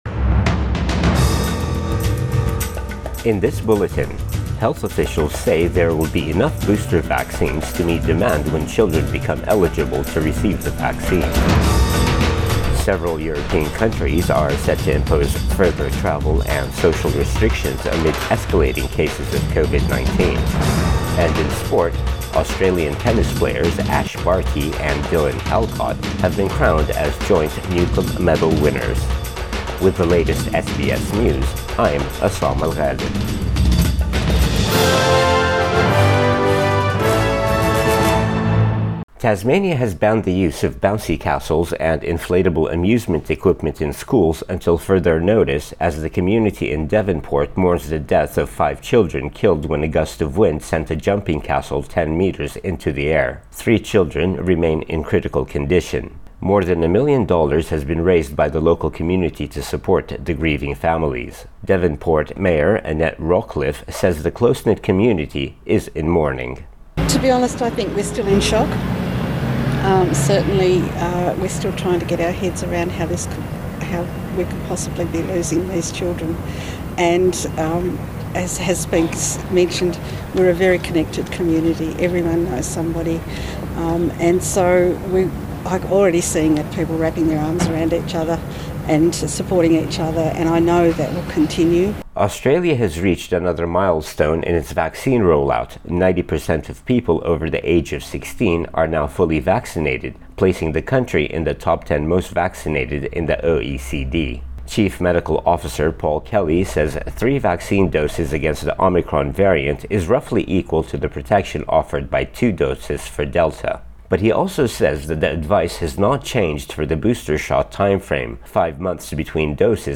AM bulletin 18 December 2021